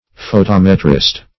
\Pho*tom"e*trist\, n.
photometrist.mp3